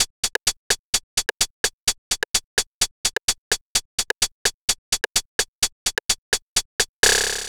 Retro Click _ Hat.wav